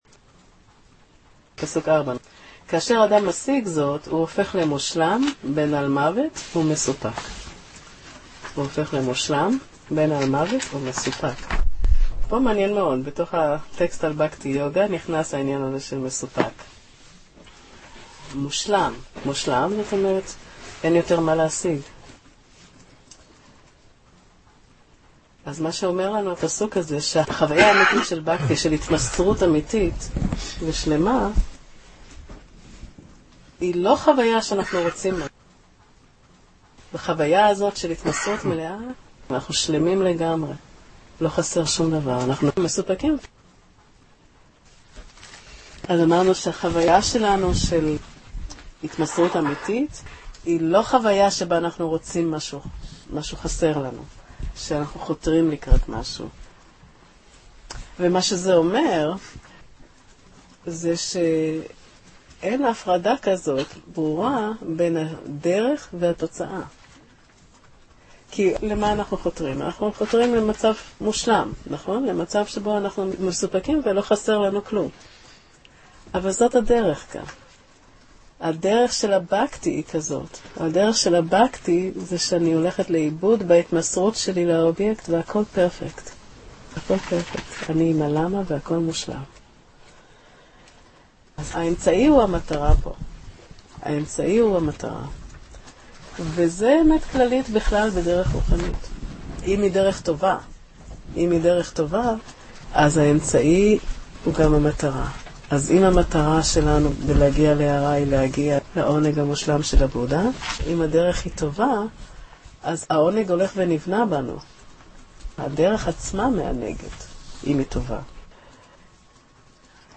הקלטות מלימוד